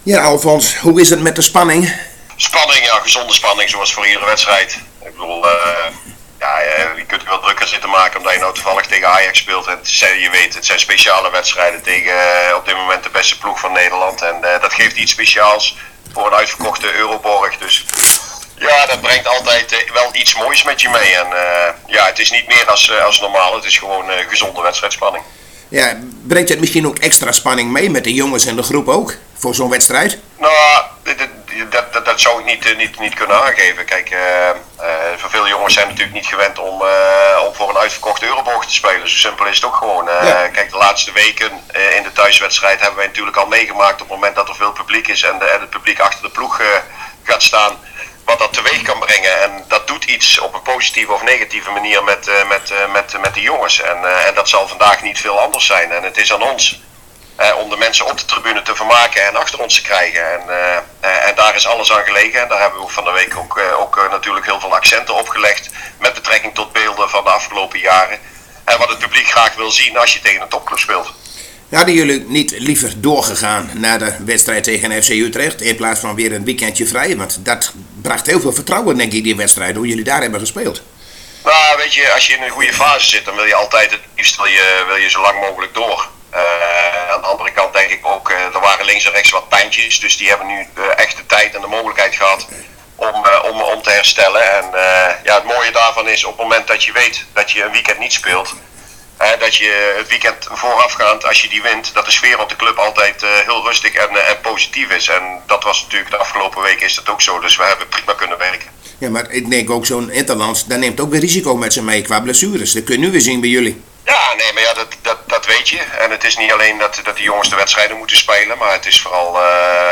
Zojuist spraken we met een zeer verkouden snuitje